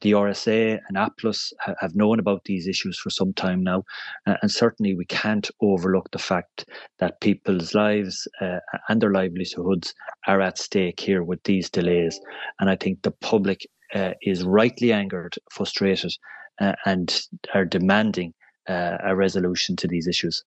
Commitee member and Fine Gael TD, Alan Dillon says the public deserves better: